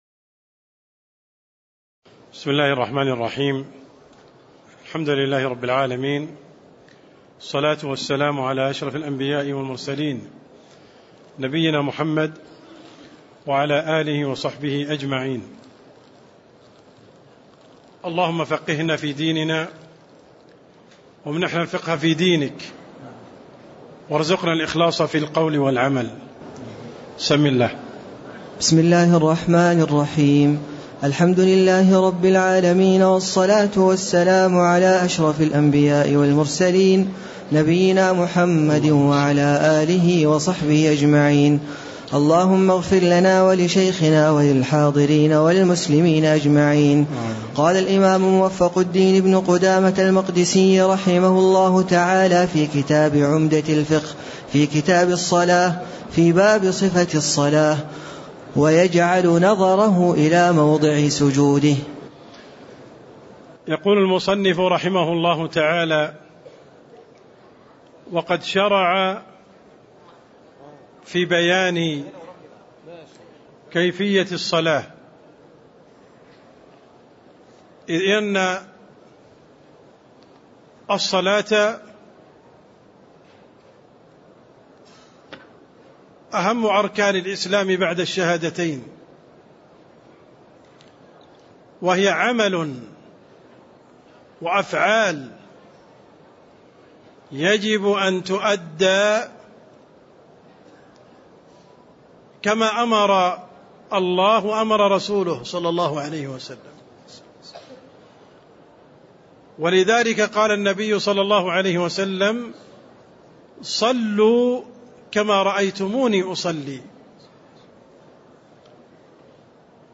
تاريخ النشر ١٧ صفر ١٤٣٦ هـ المكان: المسجد النبوي الشيخ: عبدالرحمن السند عبدالرحمن السند باب صفة الصلاة (08)